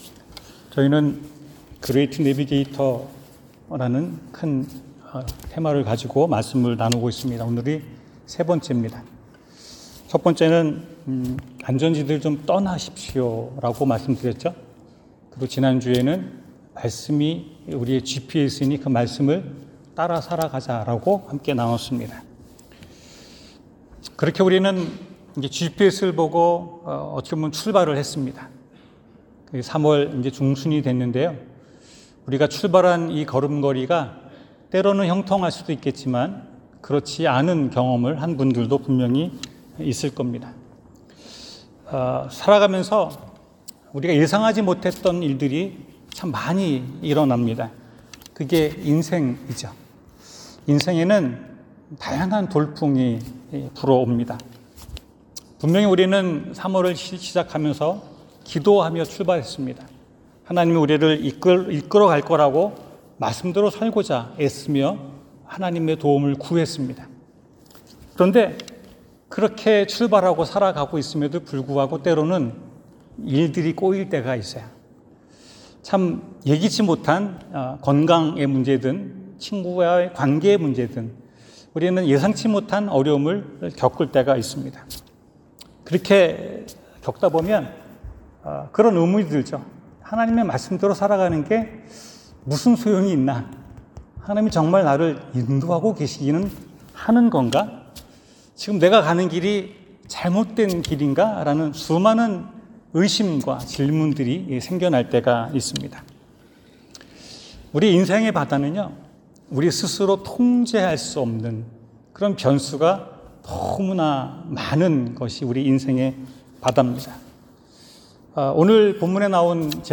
내 배의 지휘권을 이양하십시오 성경: 마가복음 4:37-40 설교